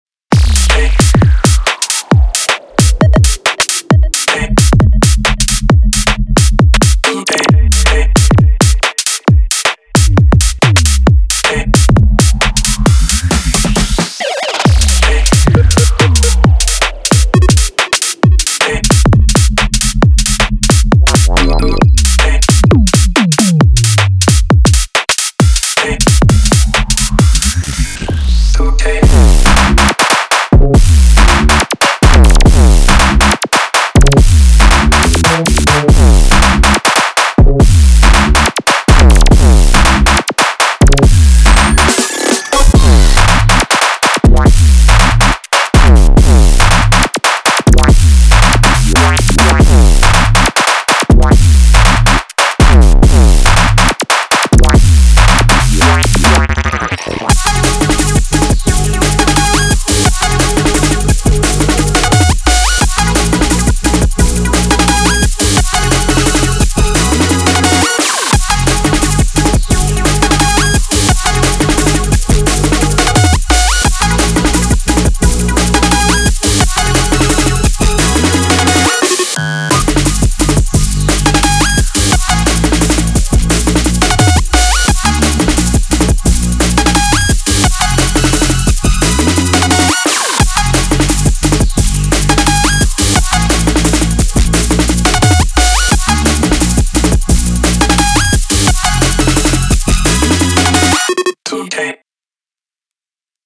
Genre:Bass Music
テンポは126から172 BPMの間で変化し、一つのジャンルにとどまりません。
レイブ、テクノ、ハードダンス、ジャングルなど、どこにでも投入可能です。
デモサウンドはコチラ↓